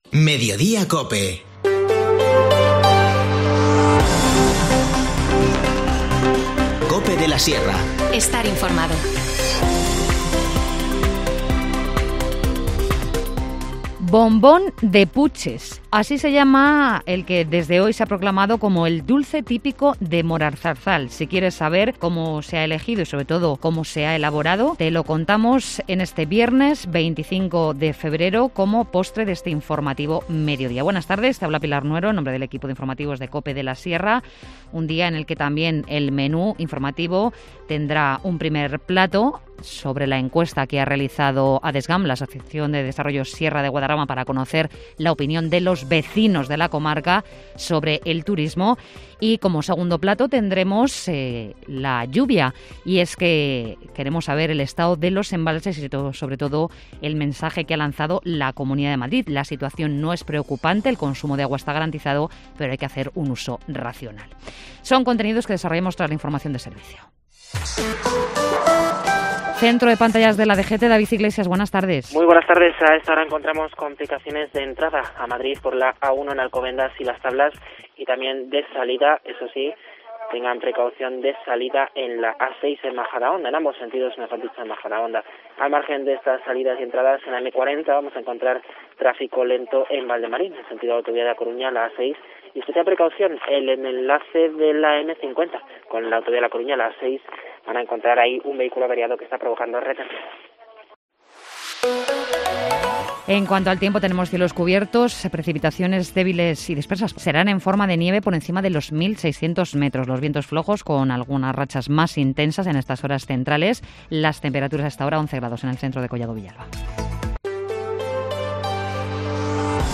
Informativo Mediodía 25 febrero